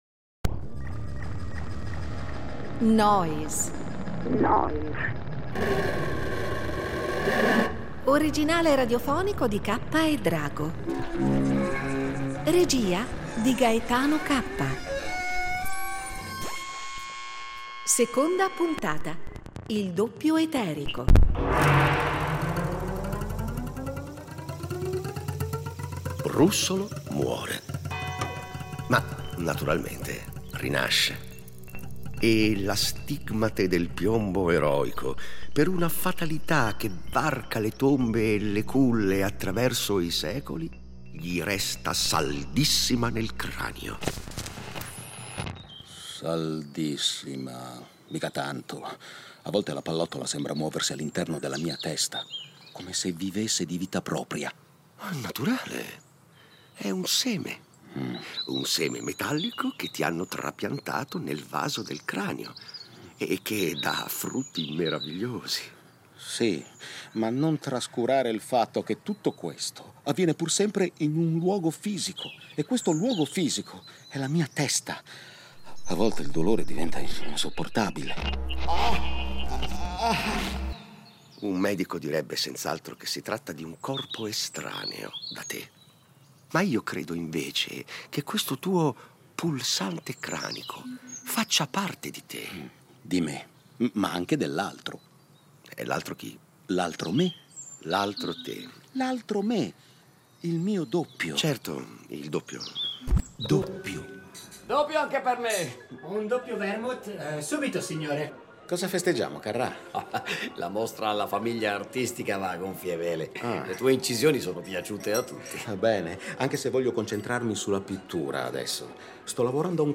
Oggi i libri di storia dell’arte si occupano di Russolo soprattutto in quanto firmatario, nel 1910, con Carrà e Boccioni, del primo manifesto della pittura futurista. Nel radiodramma abbiamo però privilegiato il Russolo musicista, compositore e inventore di strumenti sonori.